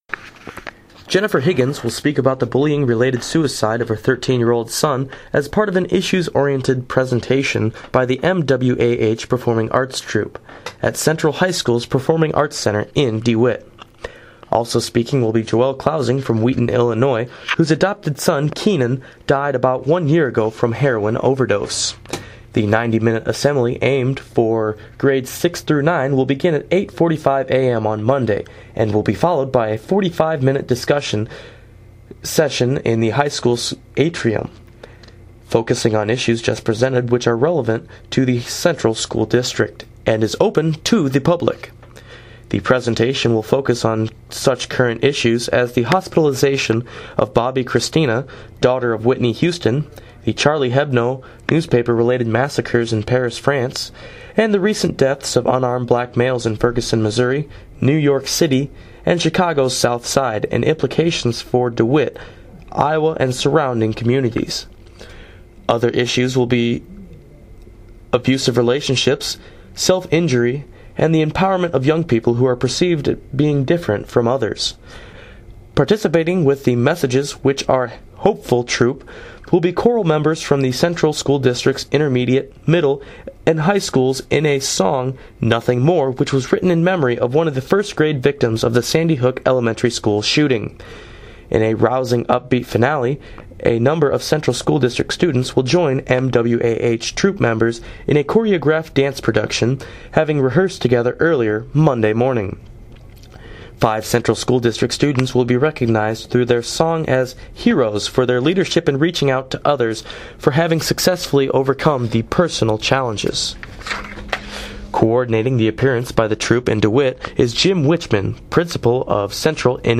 Segment of news broadcast on KMAQ Radio in Maquoketa